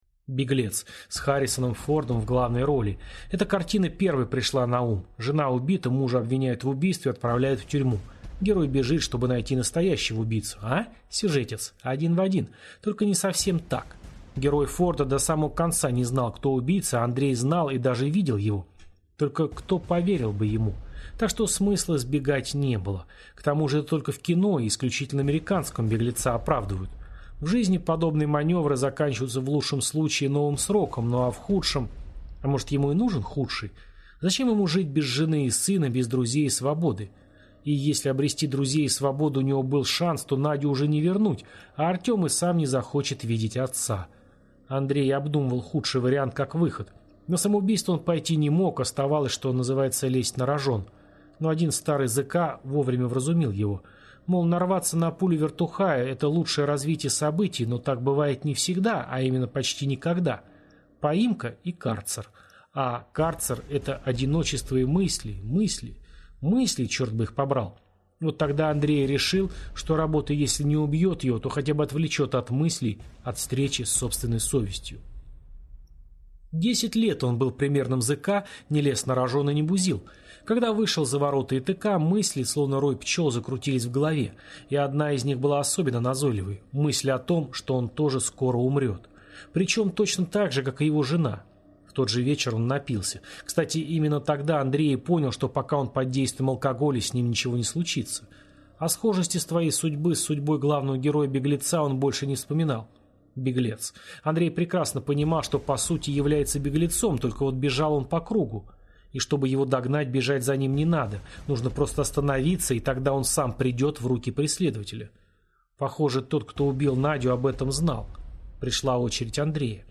Аудиокнига Запертая дверь | Библиотека аудиокниг